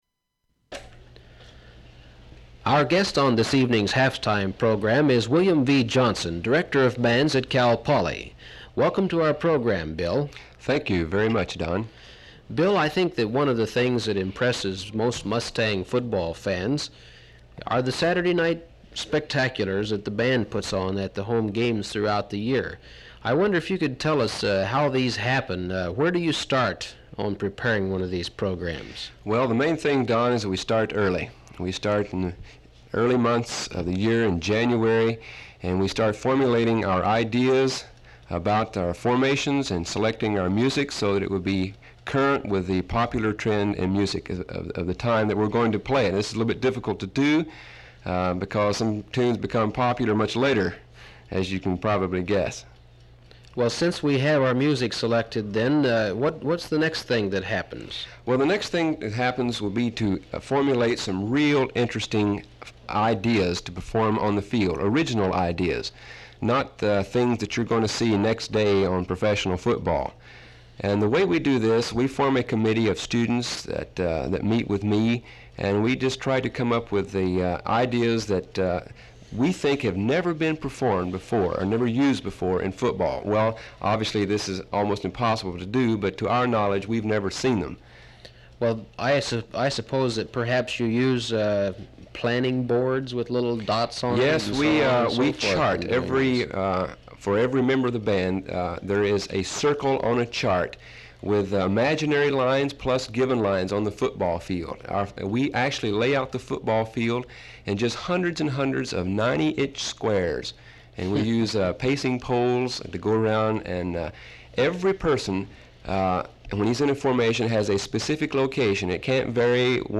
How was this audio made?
• Open reel audiotape